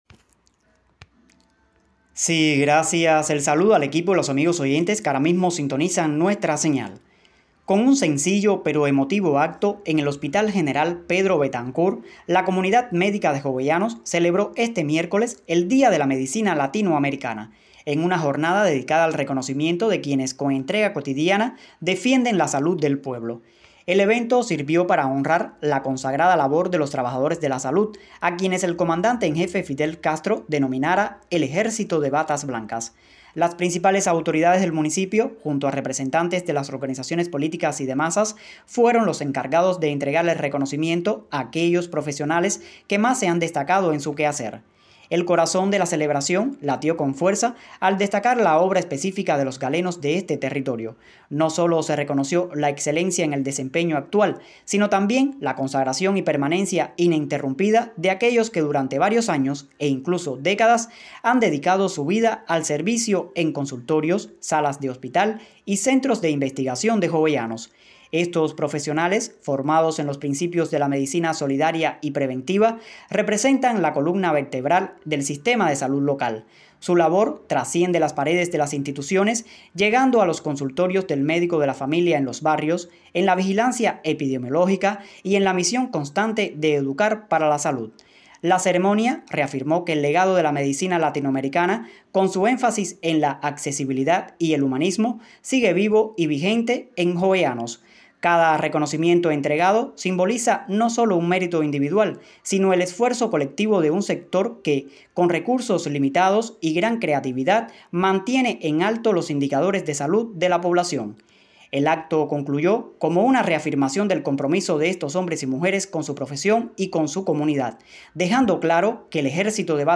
El periodista